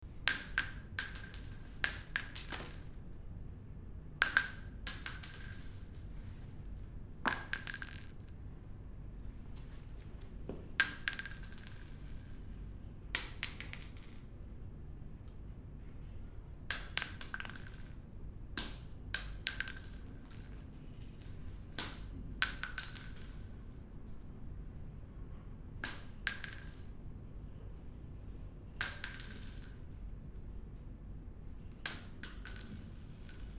Foley Bullet Drop